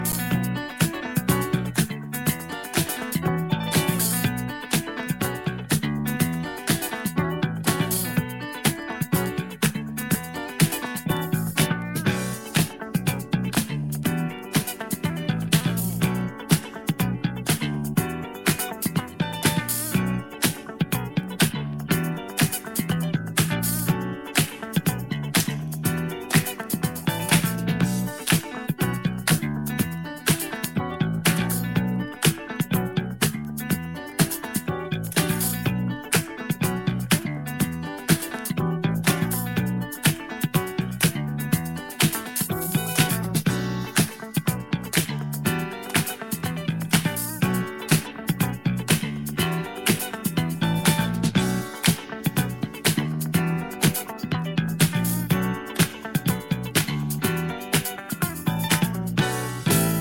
the ‘B’ side is a never before issued instrumental version